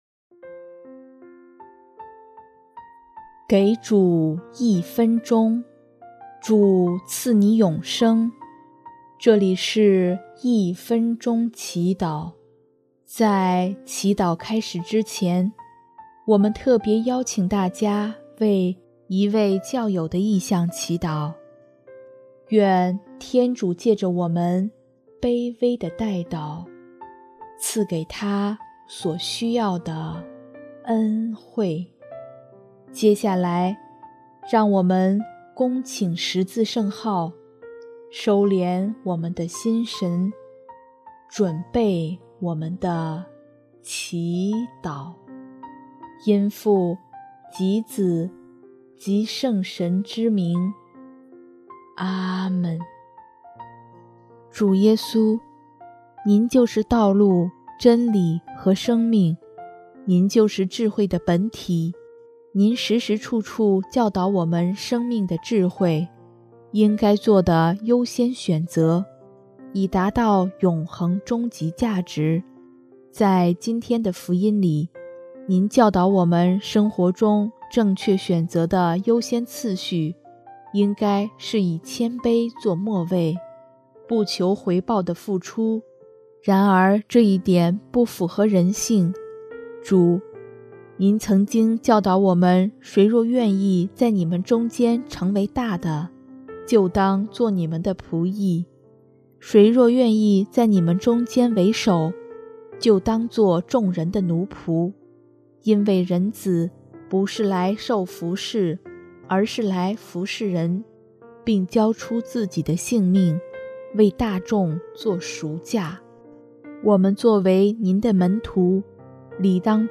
【一分钟祈祷】|8月31日 基督徒的生活观